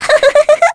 Mirianne-vox-Happy2_kr.wav